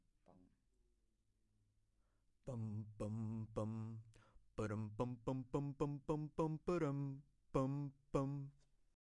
Beatbox Library " 人类低音
描述：人造贝司声音为beatbox混音。
Tag: 循环 5maudio17 节奏 UAM 节拍 节奏口技 贝司